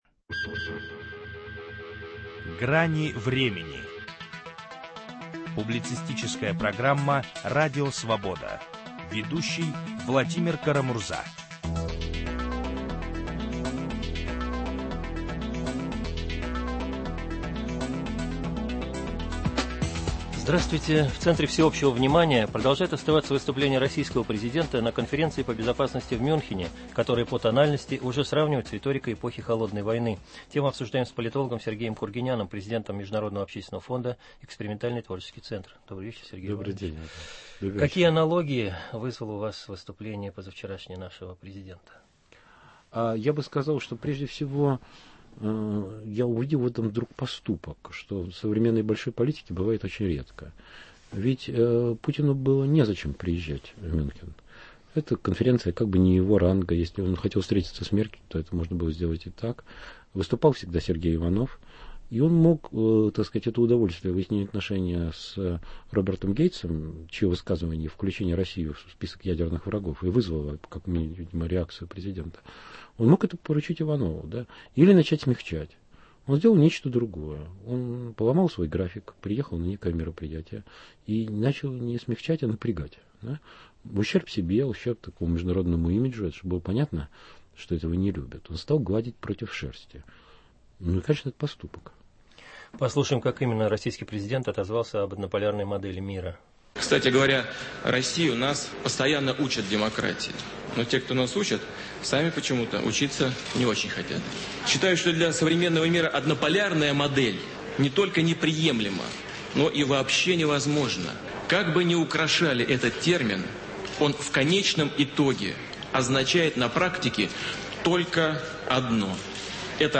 Есть разные точки зрения, многие из них - в "Гранях времени". Ведущий Владимир Кара-Мурза предлагает соглашаться, уточнять, дополнять и спорить, сделать программу дискуссионным клубом.